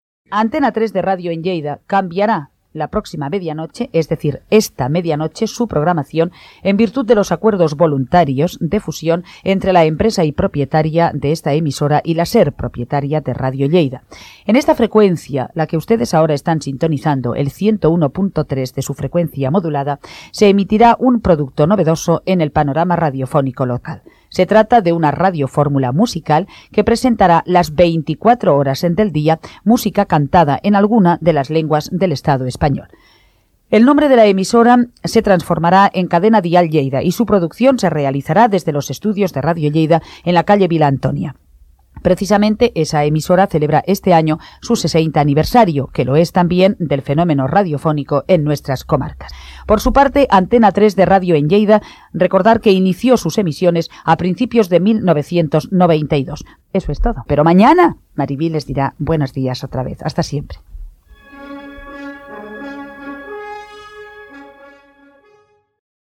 Tancament de l'últim dia d'emissió. Avís de l'inici de la radifórmula Cadena Dial Lleida, a la mateixa freqüència, i de la desaparició d'"Antena 3 de Radio en Lleida"
FM